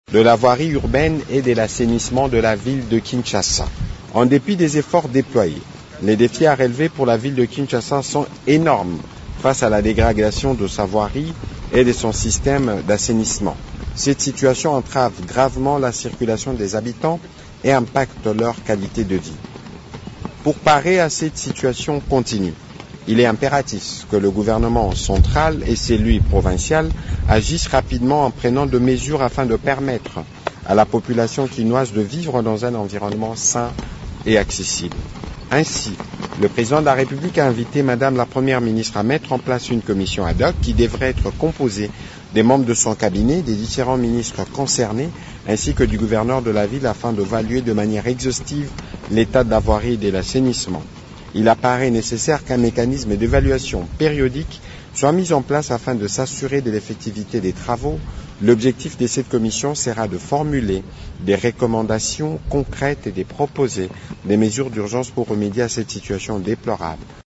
D’après le compte rendu lu par Patrick Muyaya, Porte-parole du gouvernement et ministre de la Communication, cette commission devra identifier les défaillances structurelles, les caniveaux bouchés, les constructions illégales sur les voies d’évacuation et proposer des solutions durables.
On écoute Patrick Muyaya dans cet extrait :